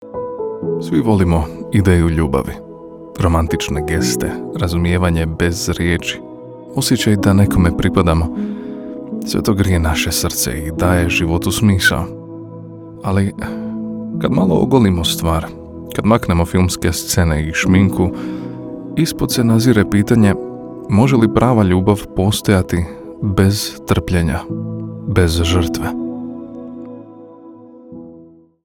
Comercial, Seguro, Amable
E-learning